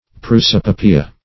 Prosopopoeia \Pros`o*po*p[oe]"ia\, n. [L., fr. Gr.